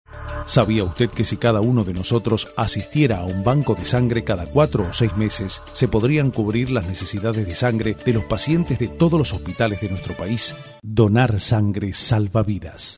Sprecher spanisch. (Südamerika) weitere Sprachen: Englisch (Britisch und Nordamerika), Italienisch.
spanisch Südamerika
Sprechprobe: Industrie (Muttersprache):